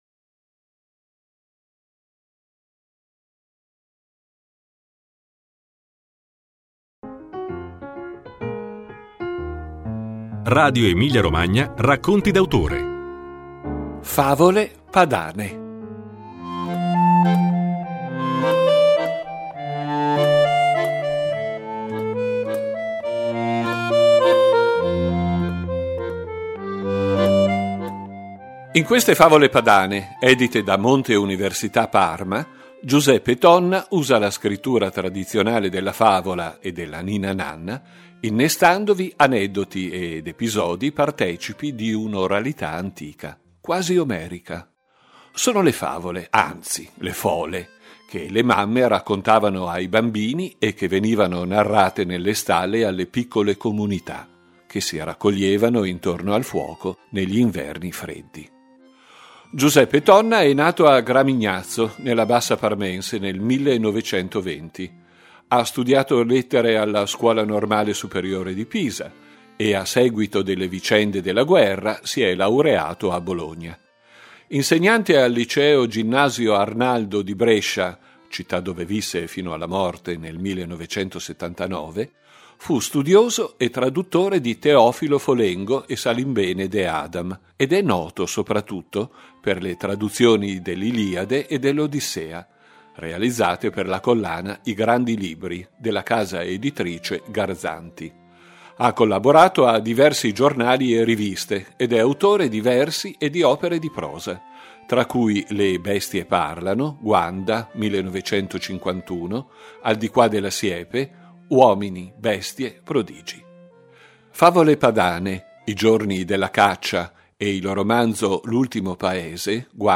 Ascoltate la puntata del 16 dicembre 2010 di Radio EmiliaRomagna con la lettura di alcune favole del libro Favole Padane